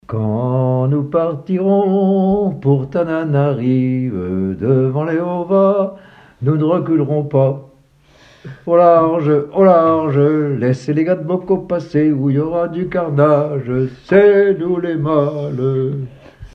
Genre brève
Témoignages et chansons
Catégorie Pièce musicale inédite